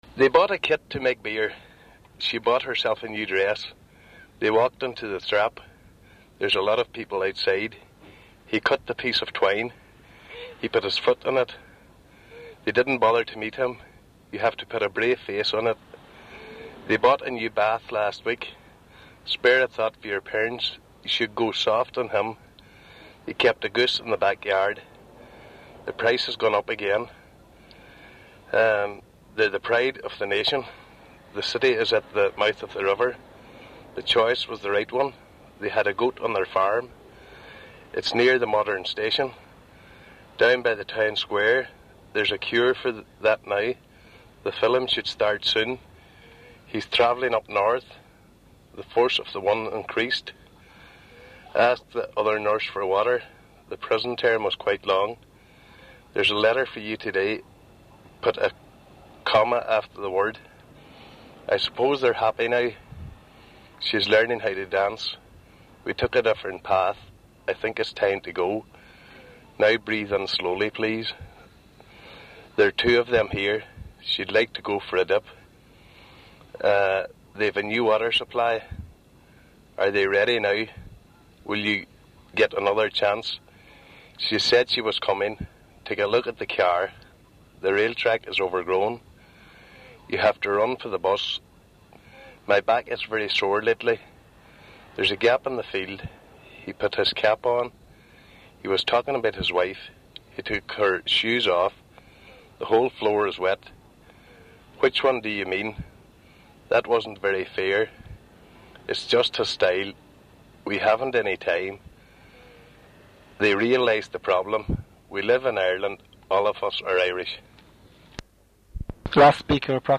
Middle-aged from north Co Donegal
DON_Carndonagh_R2_M_50.wav